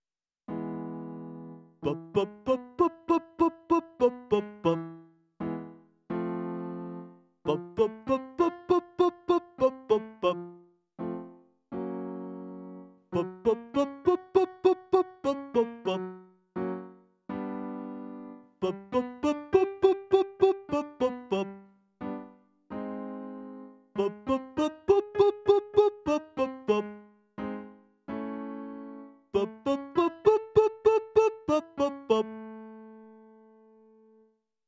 • 地声からスタートして裏声まで到達する音階で練習する
• 喉頭の位置を下げて少し溜めを作った「バッ」と発音する
• 少しずつ最高音に裏声の要素を足していくイメージ
音量注意！